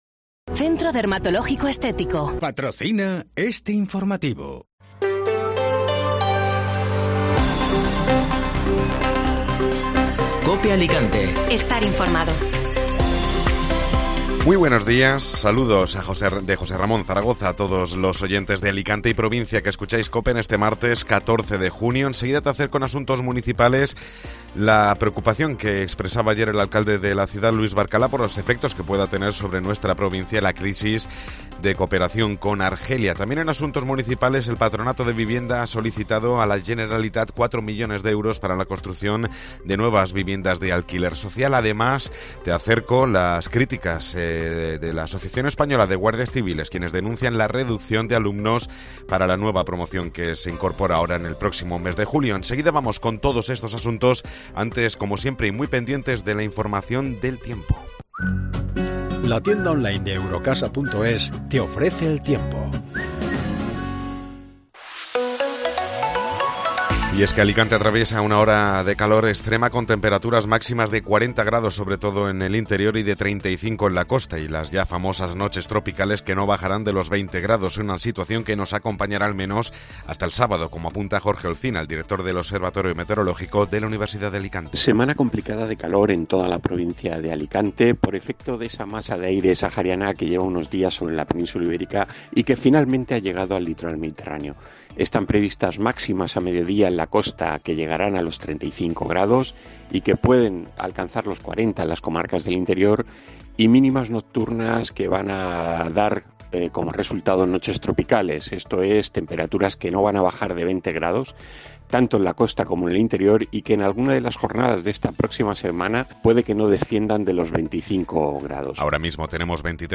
Informativo Matinal (Martes 14 de Junio)